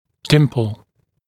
[‘dɪmpl][‘димпл]небольшой изгиб на ортодонтической дуге, обозначающий ее середину; ямочка, впадина